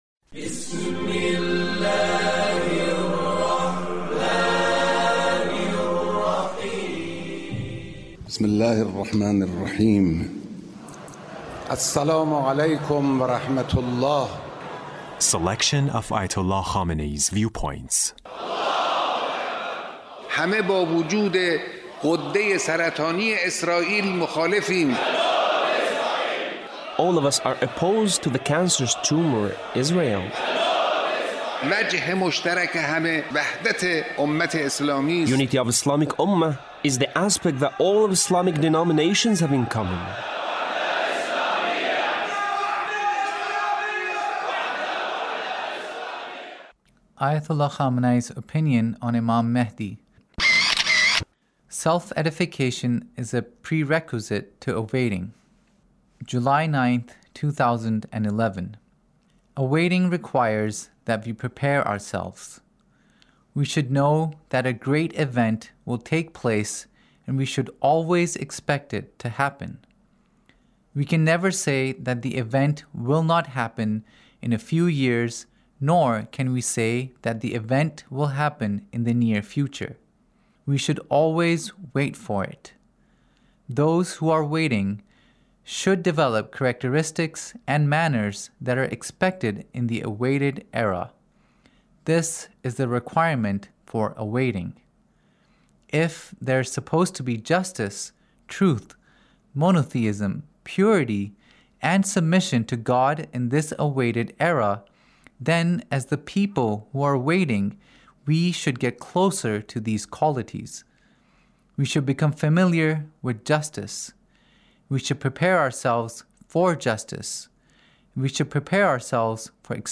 Leader's Speech (1892)
Leader's Speech on Mahdawiyya